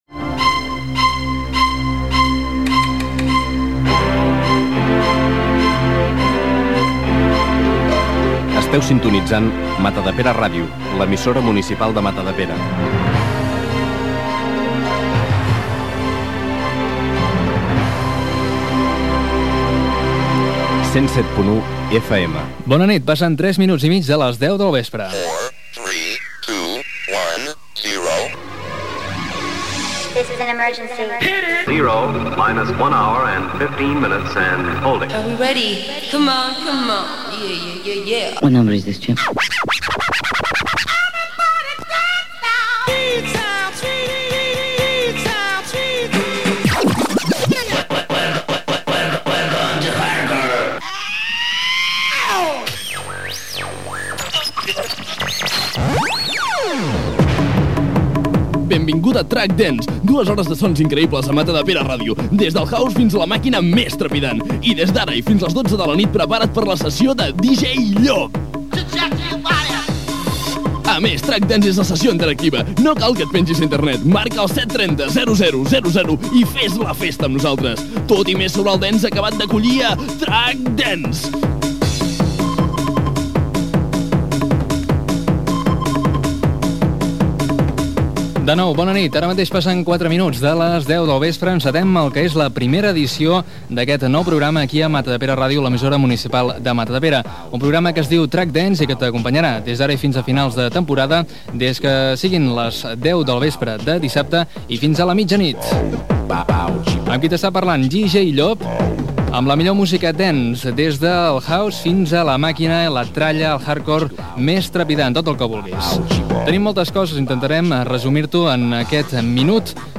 Indicatiu de l'emissora, careta del programa, introducció a la primera edició del programa i presentació d'un tema musical.
Musical